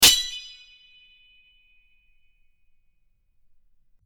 Clash_04.mp3